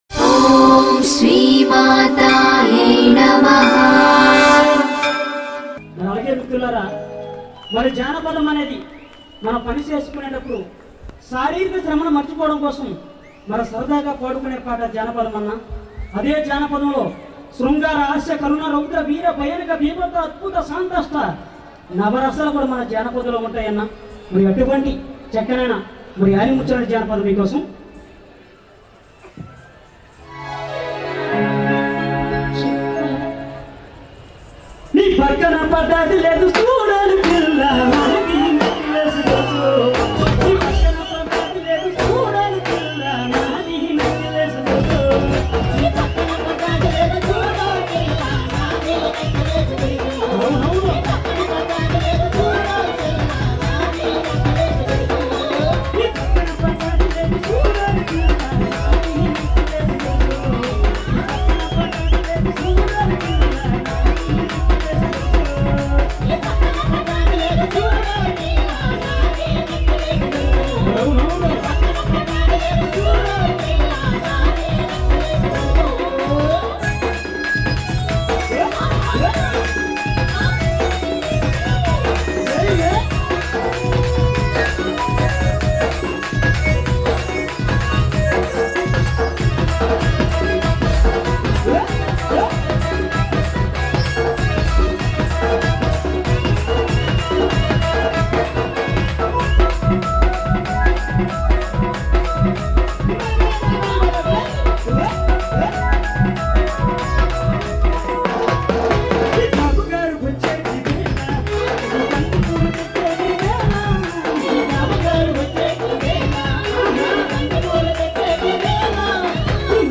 CategoryTelangana Folk Songs